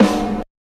SNARE 90S 8.wav